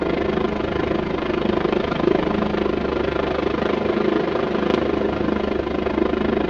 H145_IGE_Vortex_In-right.wav